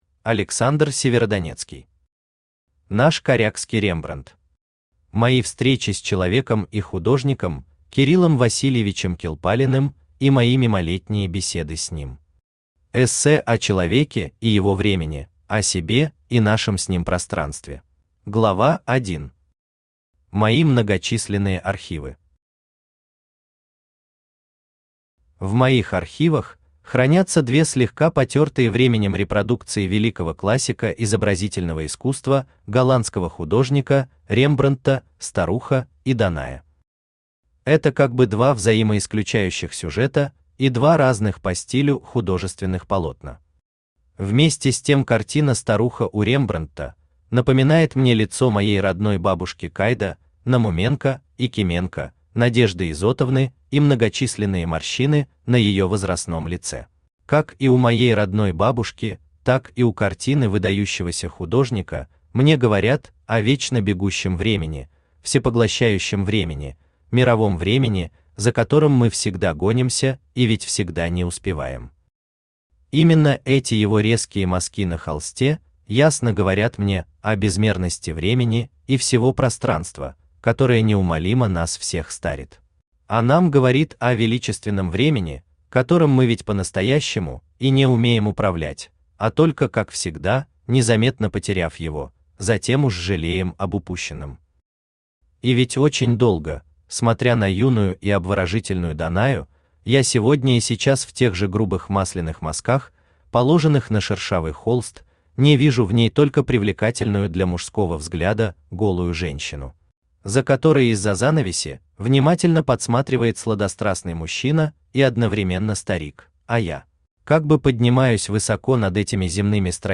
Аудиокнига Наш корякский Рембрандт.
Читает аудиокнигу Авточтец ЛитРес.